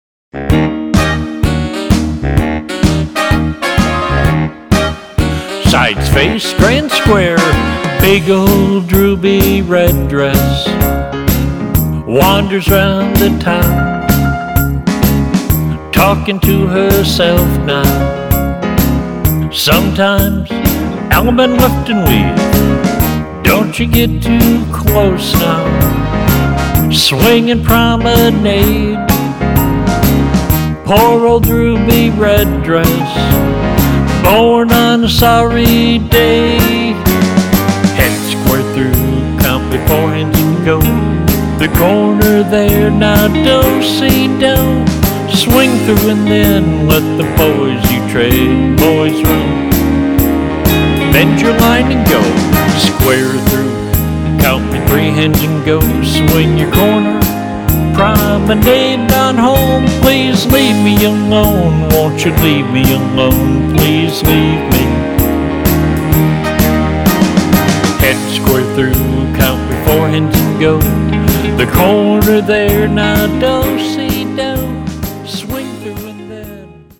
Instrumental